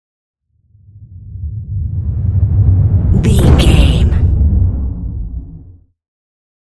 Deep whoosh to hit large
Sound Effects
dark
intense
tension
woosh to hit
the trailer effect